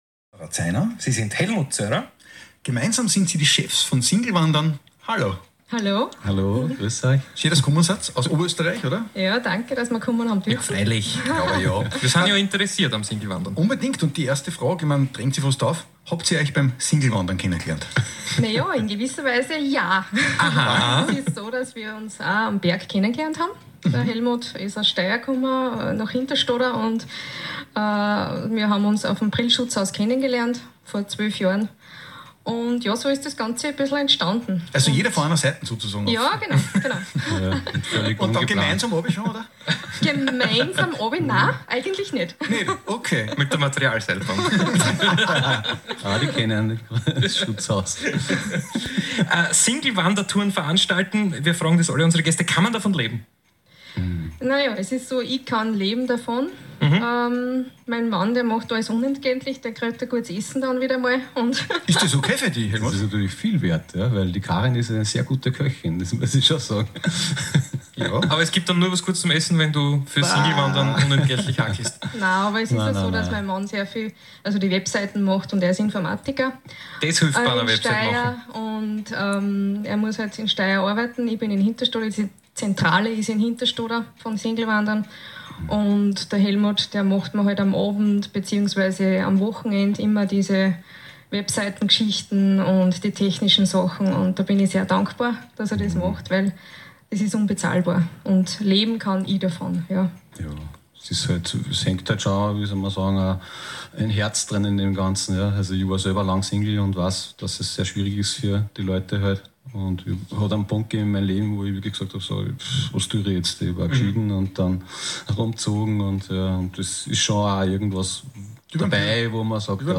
Es war eine wirklich nette Runde und eines der schönsten Radio Interviews die wir bisher geführt haben - Danke nochmal an die Beiden.